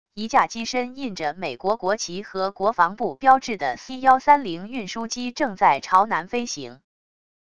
一架机身印着美国国旗和国防部标志的c130运输机正在朝南飞行wav音频